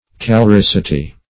Search Result for " caloricity" : The Collaborative International Dictionary of English v.0.48: Caloricity \Cal`o*ric"ity\, n. (Physiol.)